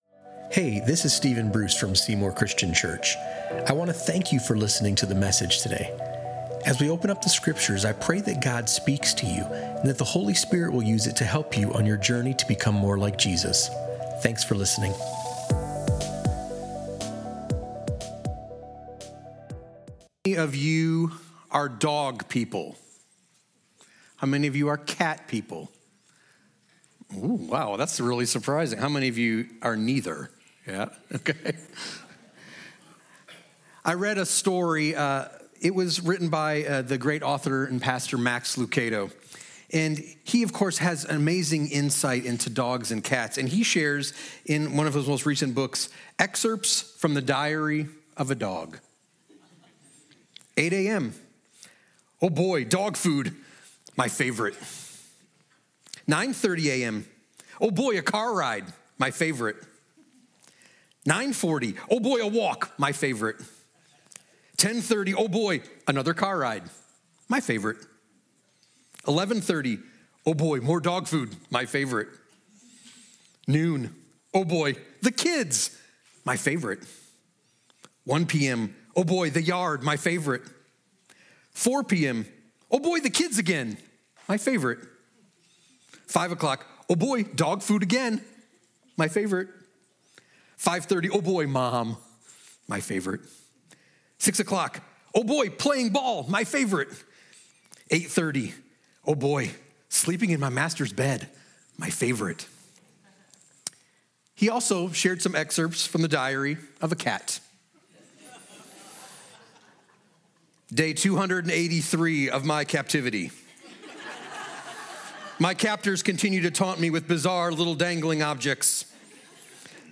Discover how gratitude becomes a life-shaping rhythm that leads to generosity. A sermon from Romans 12:9–13 at Seymour Christian Church.